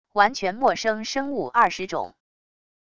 完全陌生生物20种wav音频